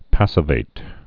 (păsə-vāt)